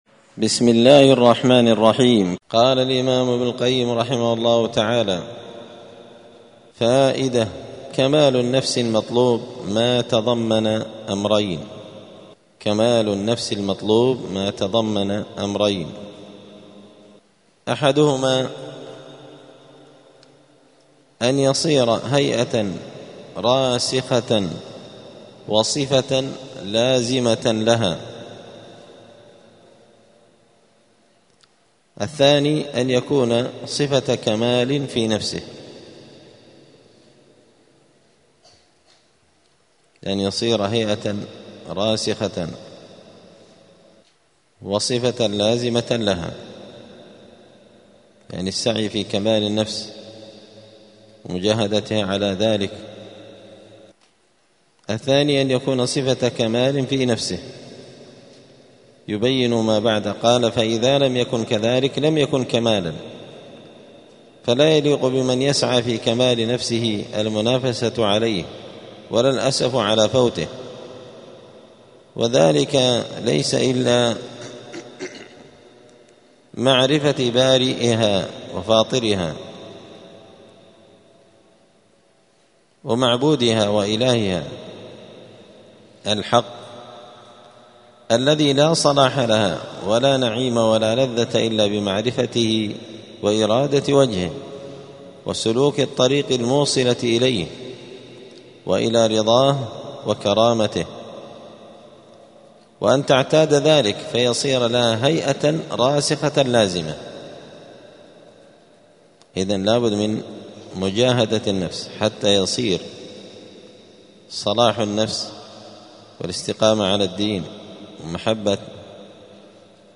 *الدرس السادس والأربعون (46) {فصل: كمال النفس المطلوب ماتضمن أمرين}*